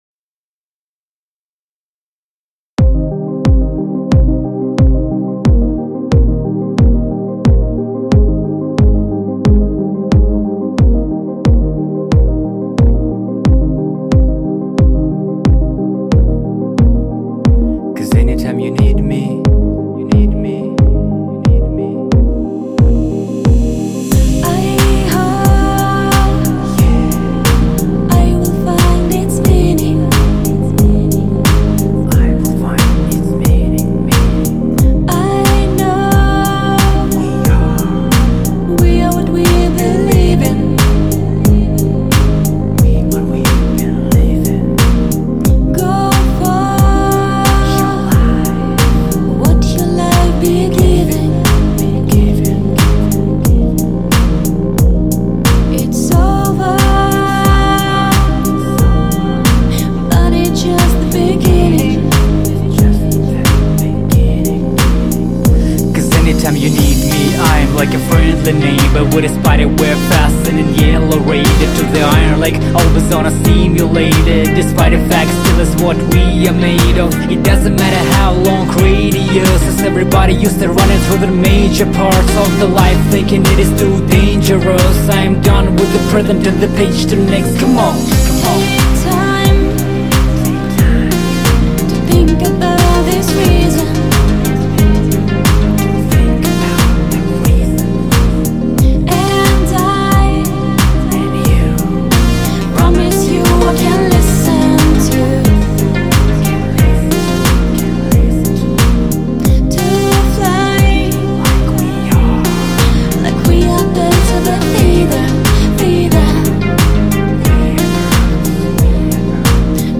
Сопрано